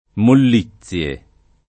vai all'elenco alfabetico delle voci ingrandisci il carattere 100% rimpicciolisci il carattere stampa invia tramite posta elettronica codividi su Facebook mollizie [ moll &ZZL e ] o mollizia [ moll &ZZL a ] s. f.; pl. -zie — latinismo per mollezza , spec. in senso morale